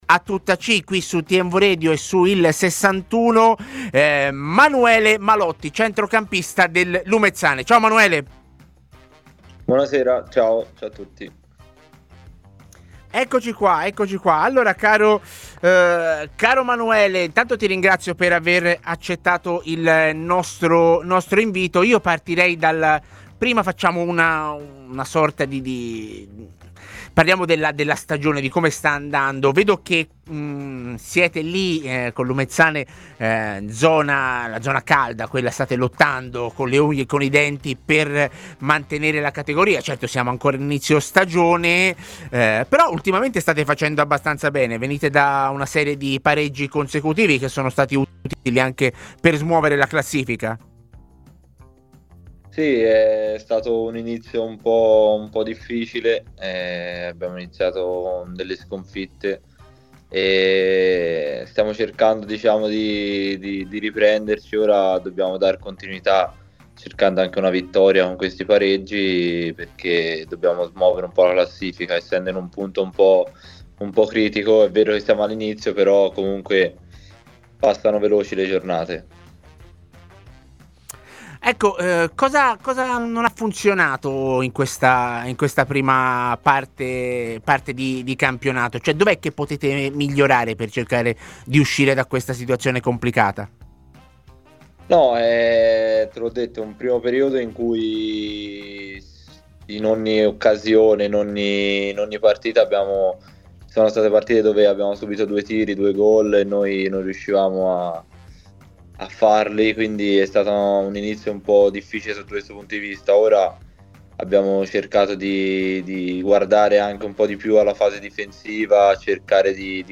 TMW Radio